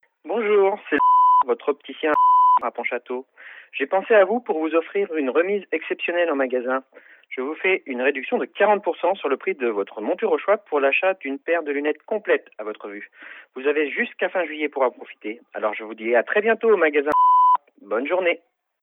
Un mailing vocal, aussi appelé SMS vocal, VMS ou encore Message répondeur, consiste à déposer en masse un message vocal directement sur le répondeur mobile de vos contacts.